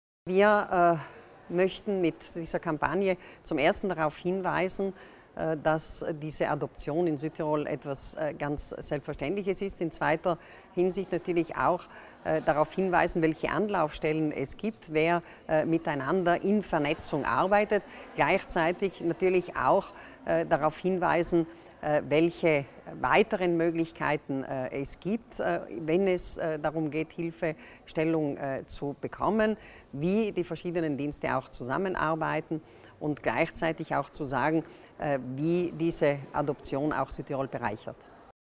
Landesrätin Martha Stocker zur Bedeutung dieser Sensibilisierungskampagne für Adoption